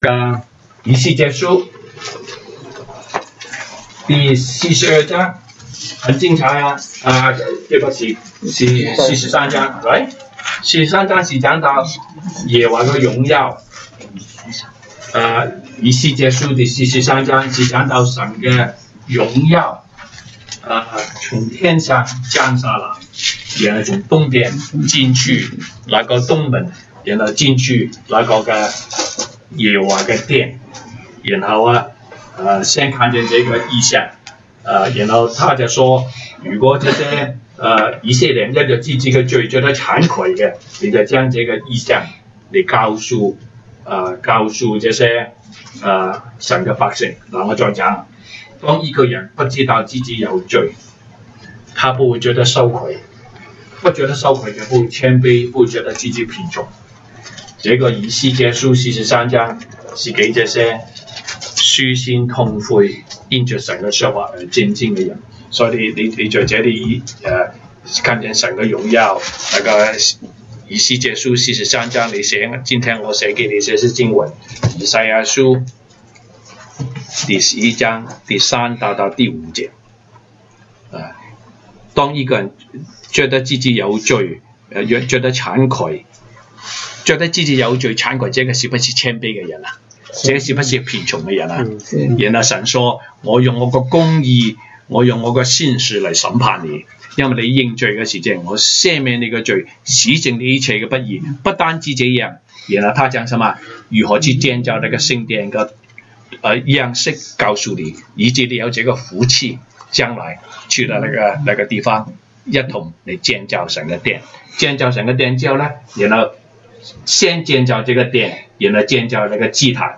Monday Bible Study